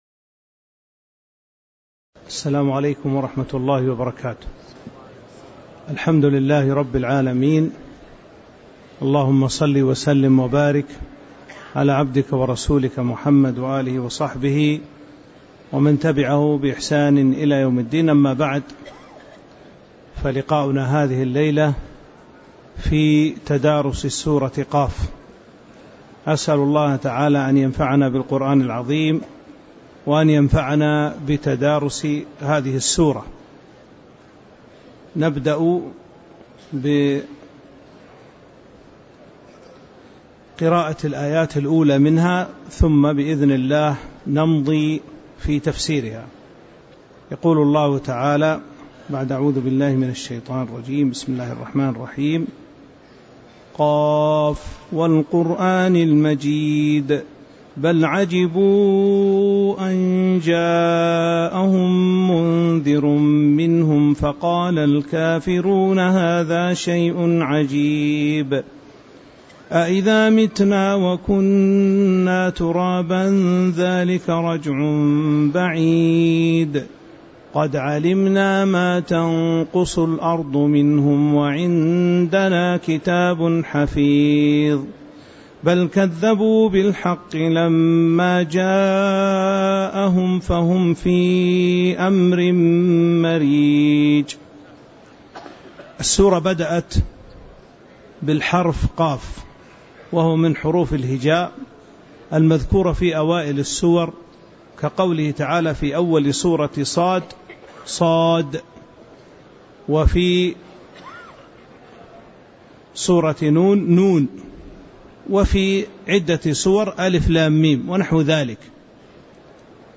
تاريخ النشر ٢٧ ذو الحجة ١٤٤٥ هـ المكان: المسجد النبوي الشيخ